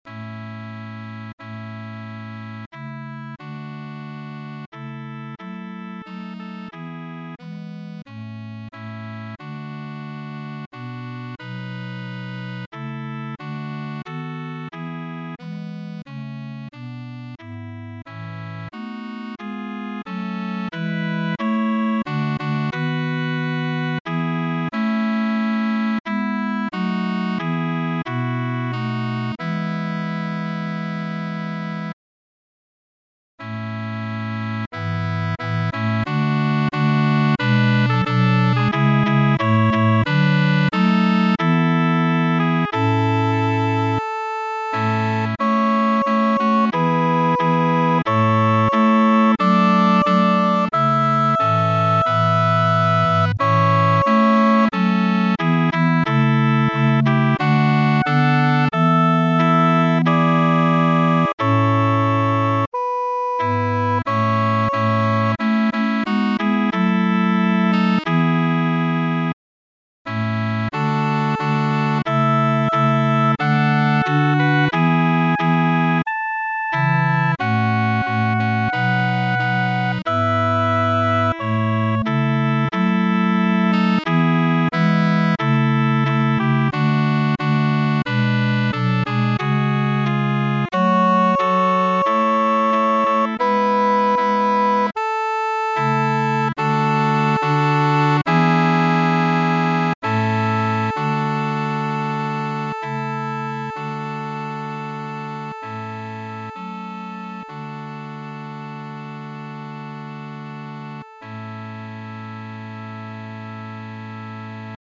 SATB + S (4 gemischter Chor + Kinderchor Stimmen) ; Partitur. Verlag: EMEjournal Eberhard Emmerich. geistlich. Barock.
Charakter des Stückes: hoffnungsvoll Chorgattung: SATB + S (4-stimmiger gemischter Chor + Kinderchor ) Solisten: solo, Congregation (1 Solist(en))
Tonart(en): A-Dur